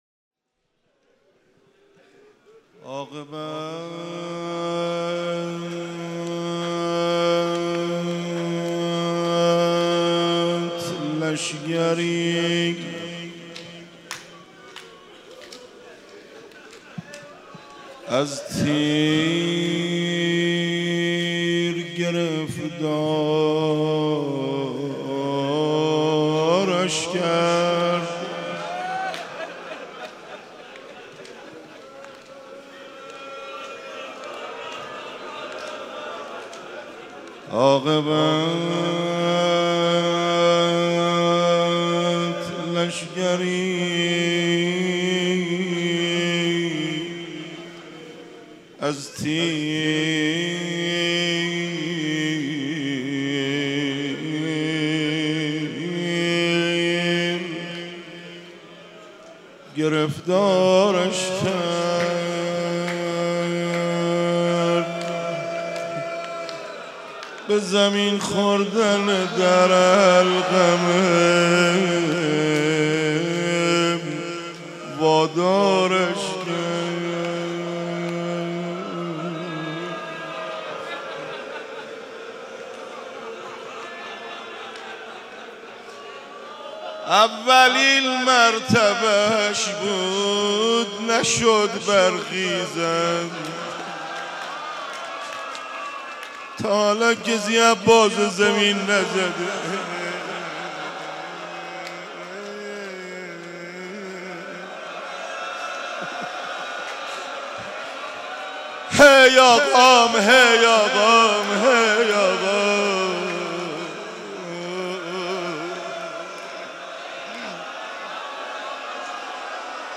شب تاسوعا محرم95/مسجد حضرت امیر(ع)